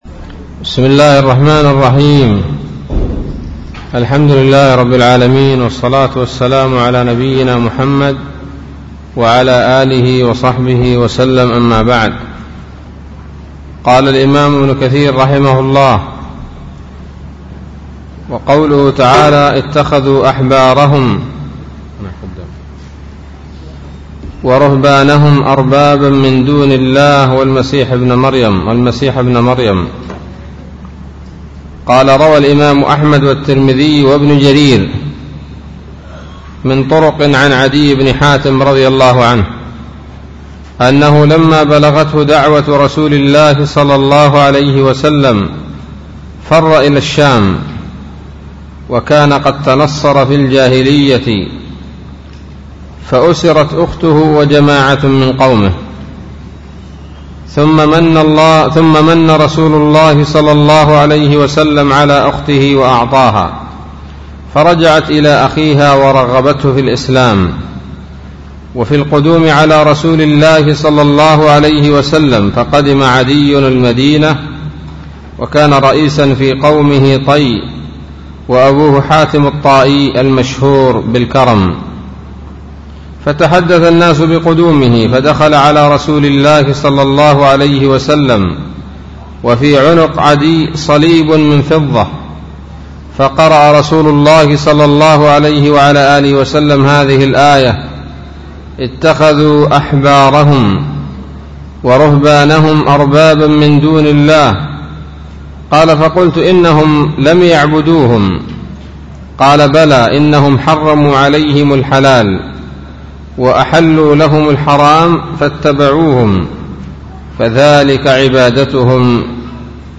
الدرس الثالث عشر من سورة التوبة من تفسير ابن كثير رحمه الله تعالى